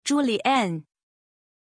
Pronunciation of Julián